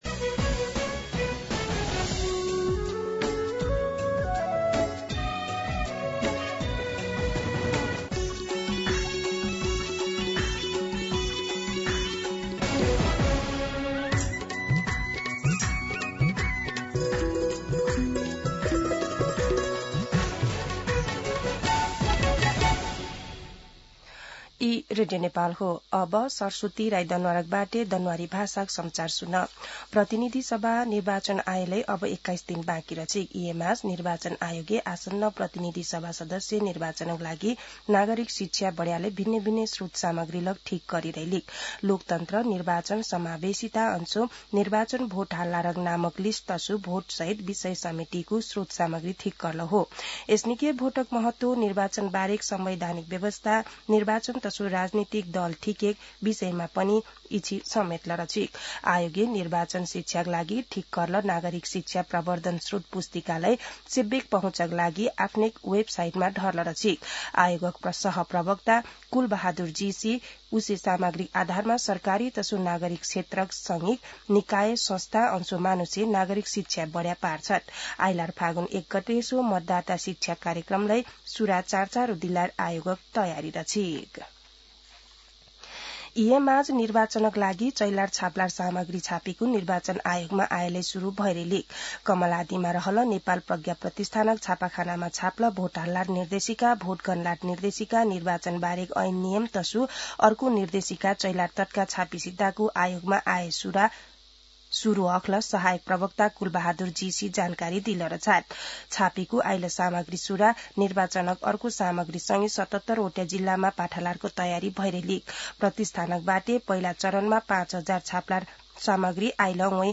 दनुवार भाषामा समाचार : २९ माघ , २०८२
Danuwar-News-10-29.mp3